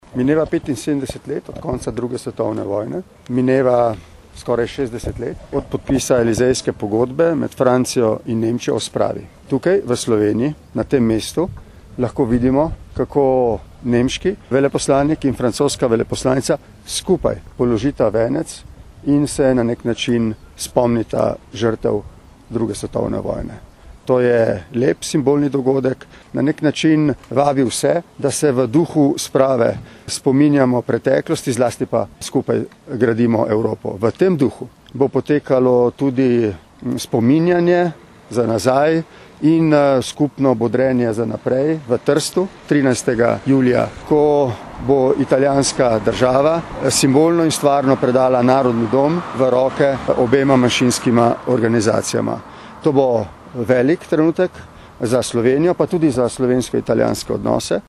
izjava_borutpahorpredsednikrs.mp3 (1,4MB)